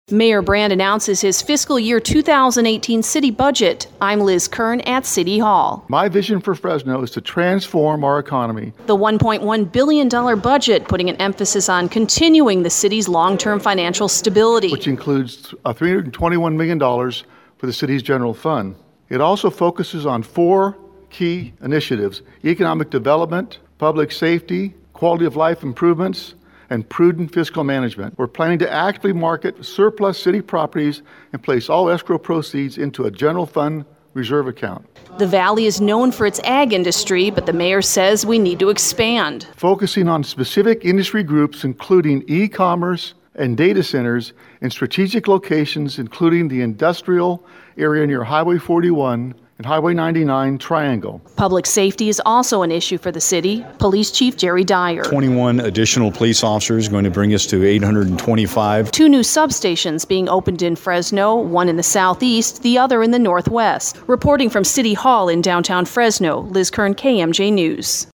Mayor Lee Brand presents his 2018 Fiscal Year budget for the City of Fresno.